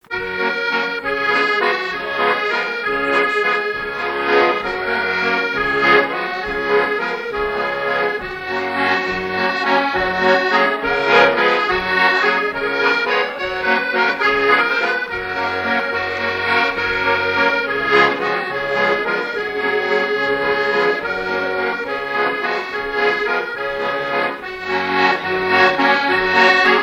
danse : valse
circonstance : fiançaille, noce
Pièce musicale inédite